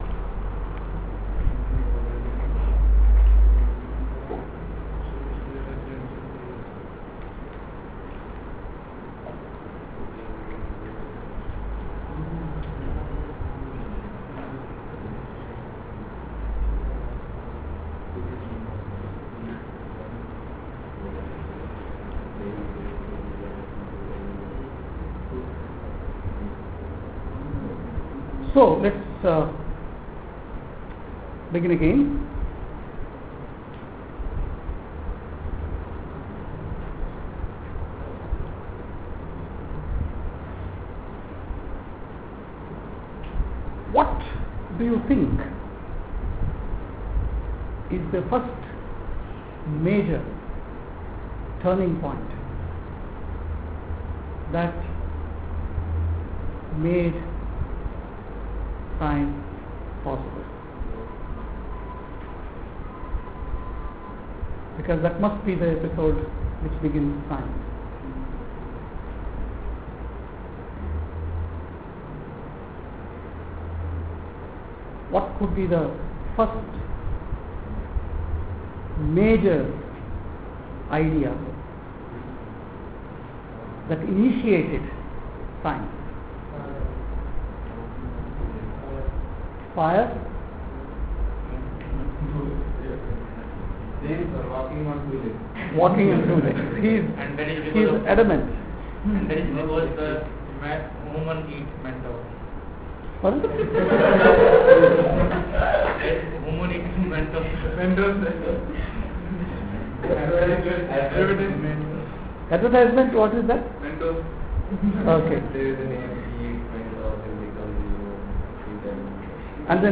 lecture 1